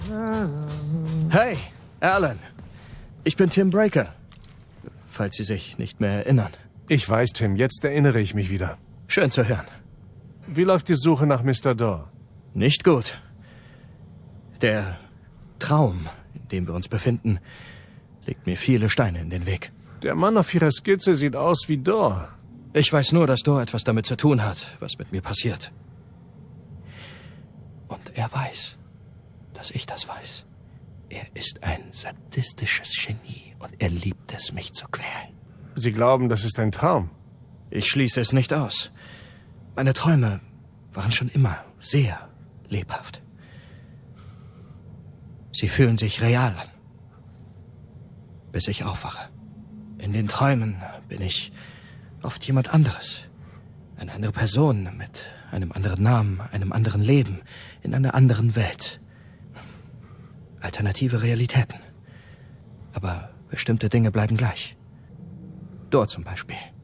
sehr variabel, dunkel, sonor, souverän, markant
Mittel minus (25-45)
Norddeutsch
Game